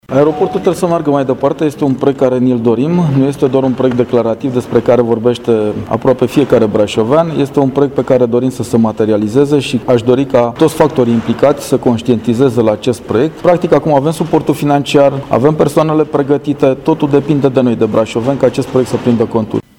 Adrian Veștea, președintele CJ Brașov: